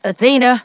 synthetic-wakewords
ovos-tts-plugin-deepponies_Applejack_en.wav